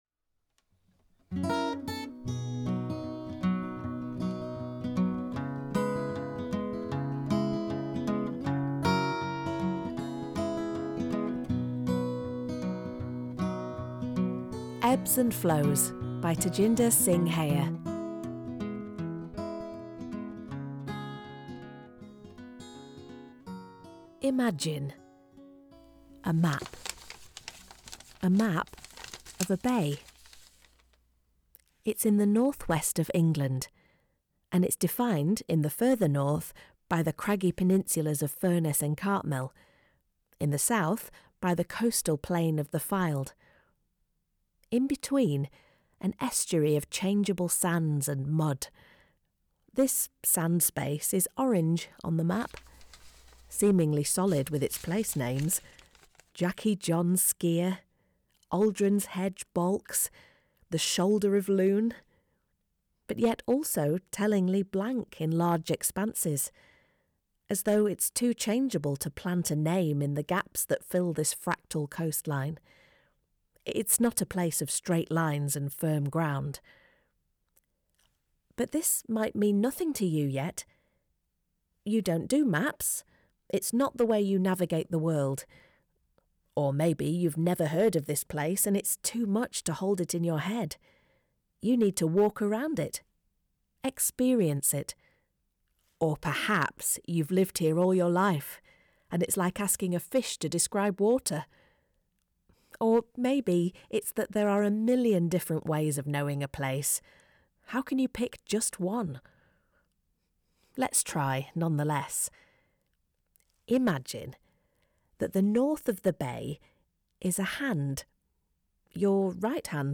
A collaboration with Lancaster University about Morecambe - the people and the place. Conversations recorded on location with residents of Morecambe edited together with a poetic narrative.